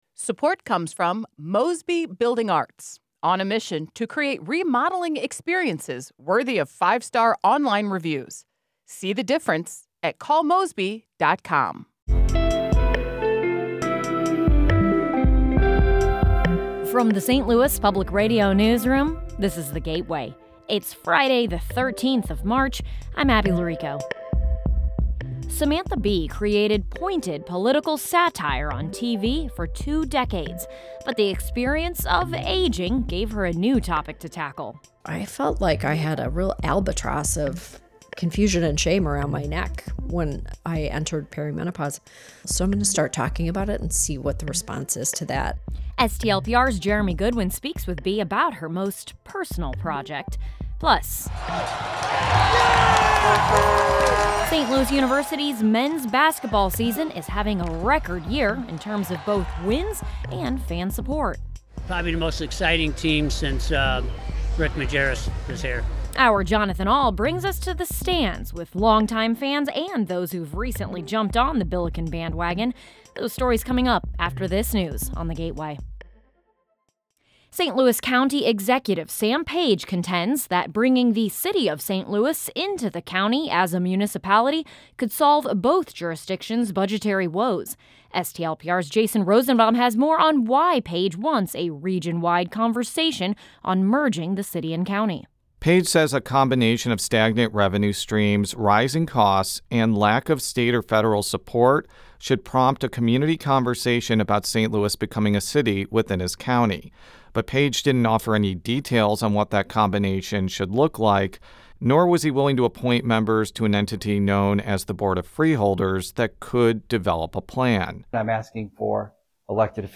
Plus, a conversation with comedian Samantha Bee ahead of her local show.